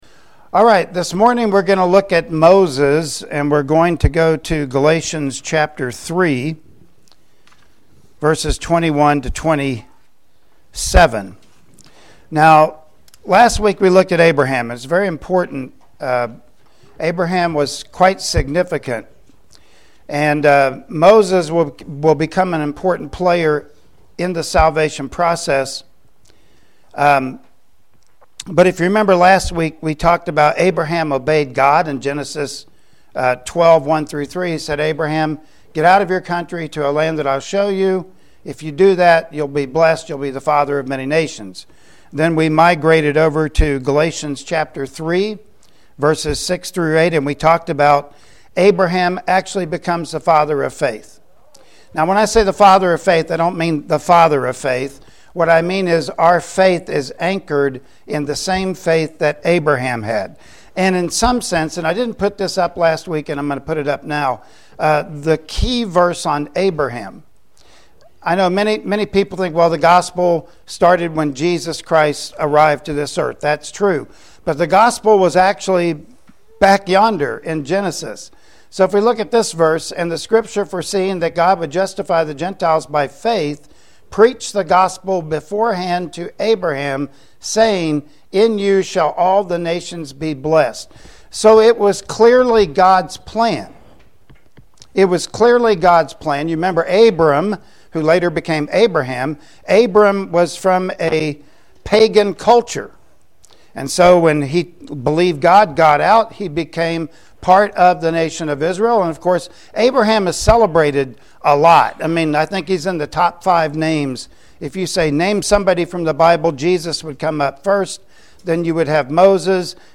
Galatians 3:21-27 Service Type: Sunday Morning Worship Service Topics: The Law & Faith